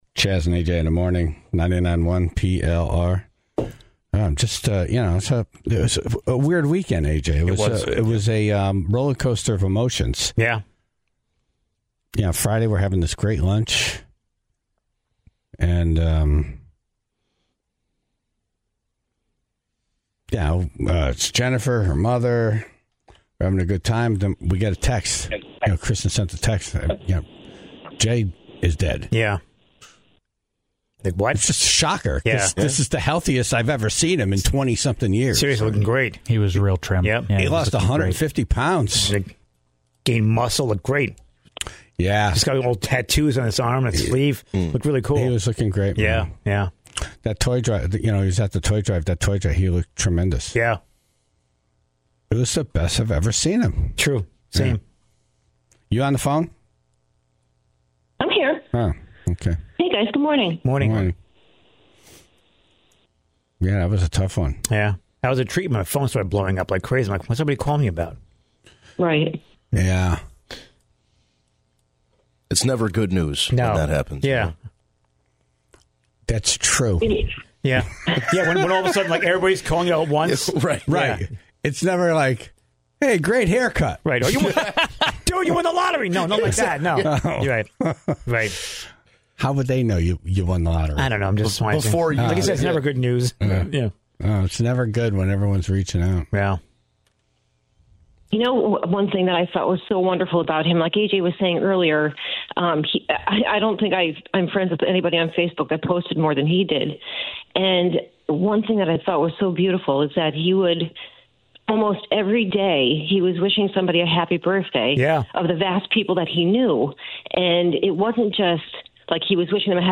Callers from the Bridgeport community, his friend group, and family members all called in to share stories